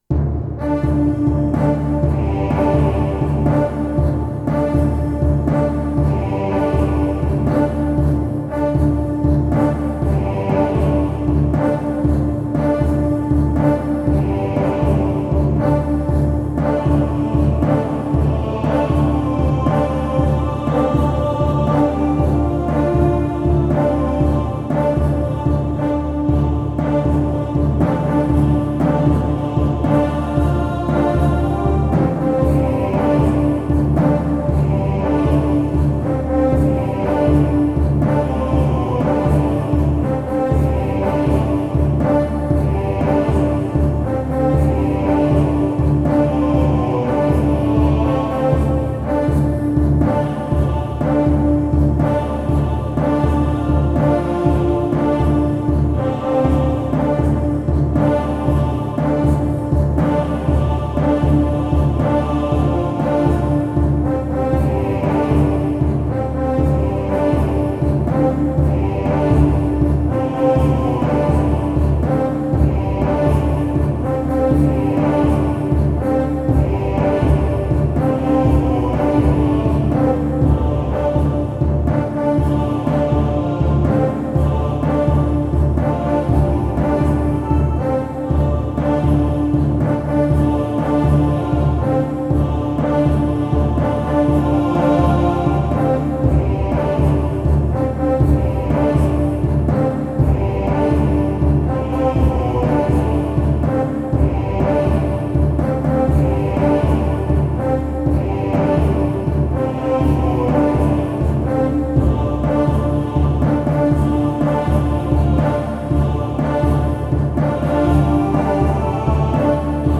Epic Soundtrack.